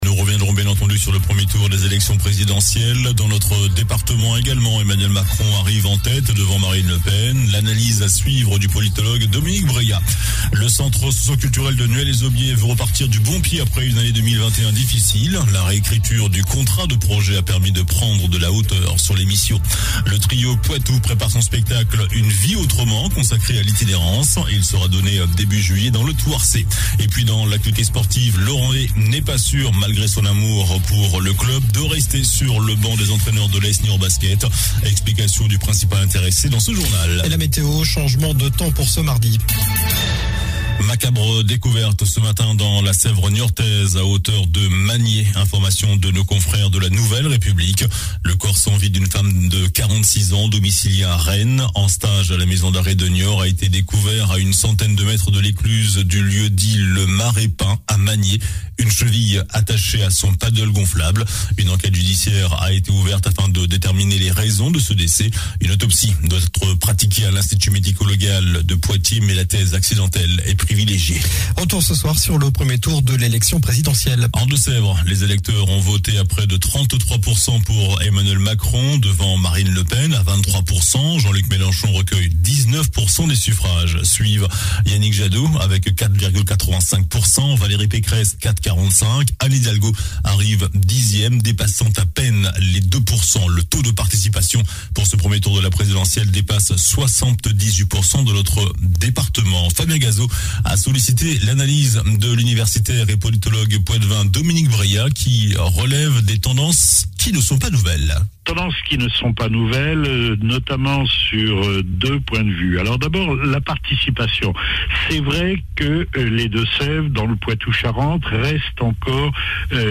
Journal du lundi 11 avril (soir)